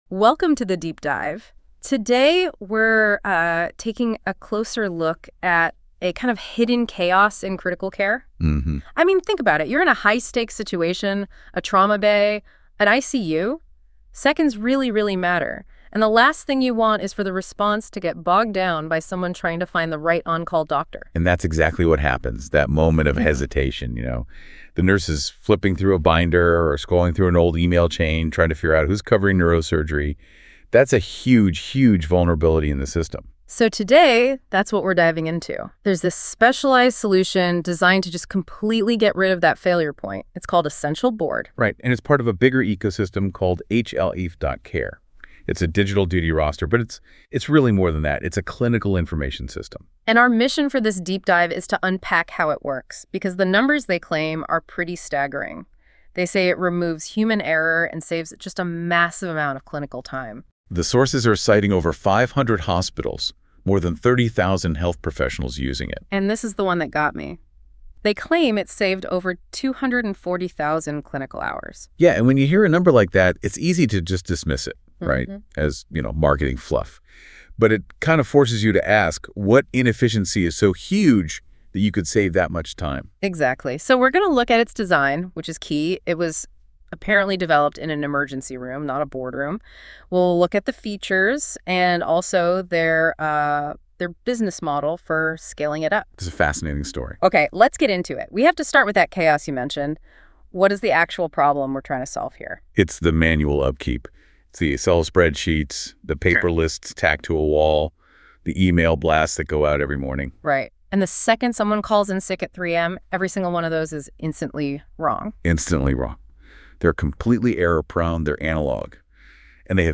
This is quite spooky: we give the documentation of ESSENTIAL BOARD, an emergency-duties screen for emergency rooms, to an AI, and it creates a podcast based on that. Two hosts discuss the solution.